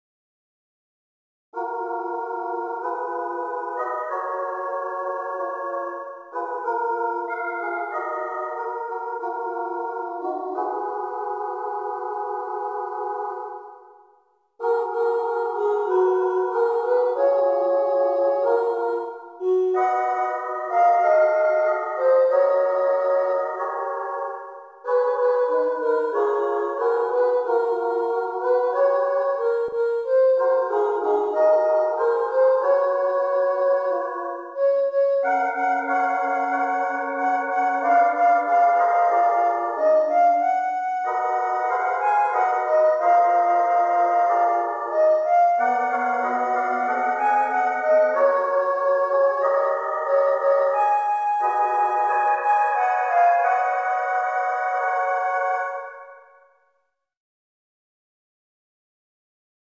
アカペラコーラス
パートも減らしているので当然完コピのつもりはない。